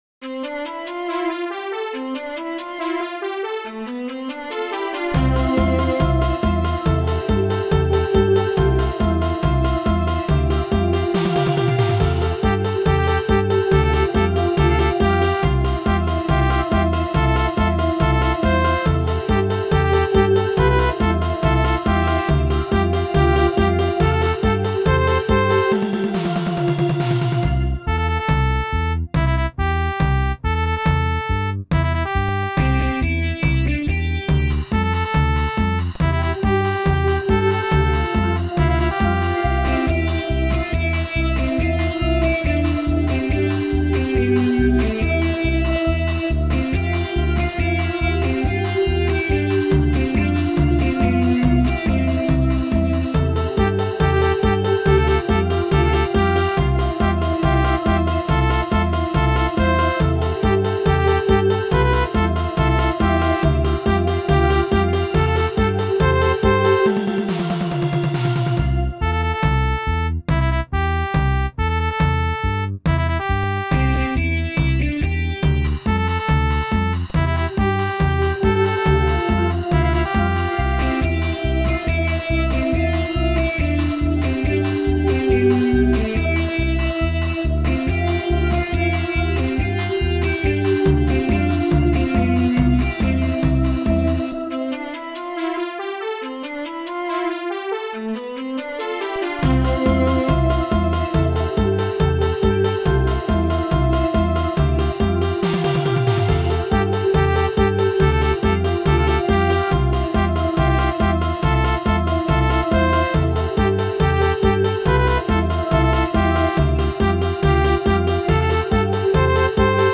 с помощью компьютера и синтезатора
Минусовка